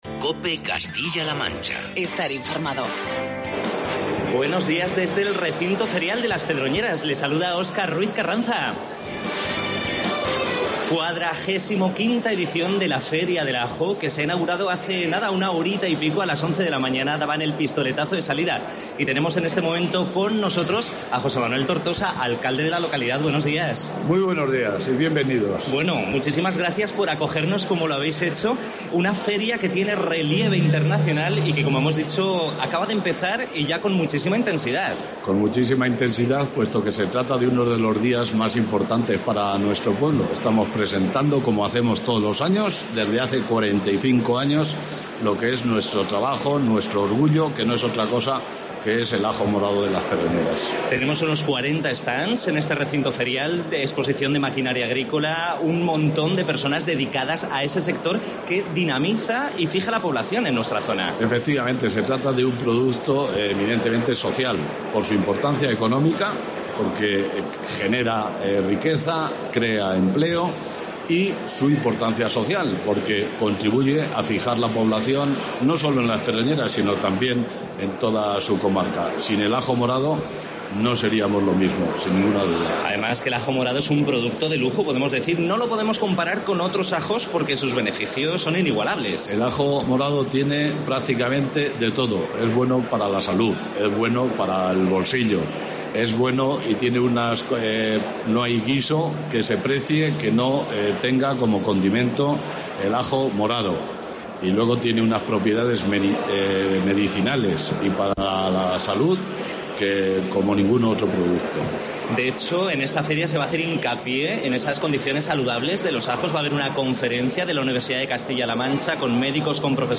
COPE Castilla-La Mancha se ha trasladado a la XLV edición de la Feria Internacional del Ajo de las Pedroñeras para acercar a todos los castellanos manchegos las propiedades de este producto tan nuestro, desde la denominada "Capital Mundial del Ajo", desde las Pedroñeras (Cuenca).
En este primer tramo el programa está con nosotros el Alcalde de las Pedroñeras: José Manuel Tortortosa